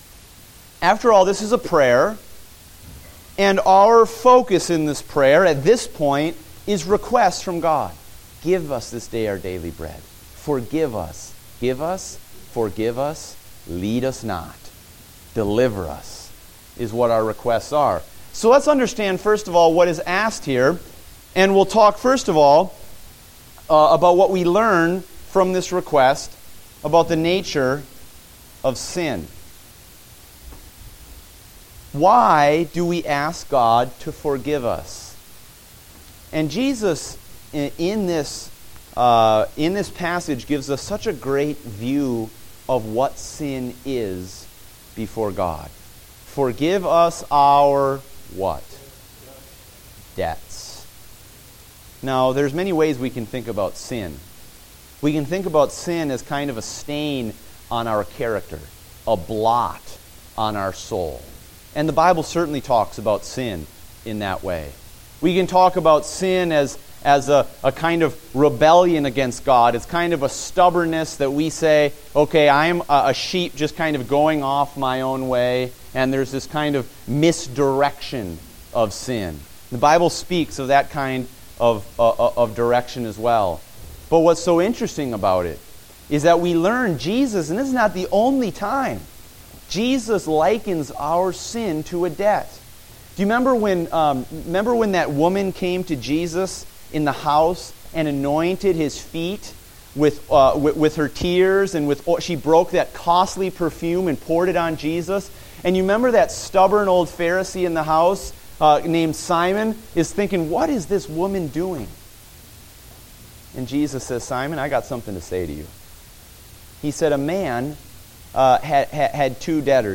Date: July 26, 2015 (Adult Sunday School)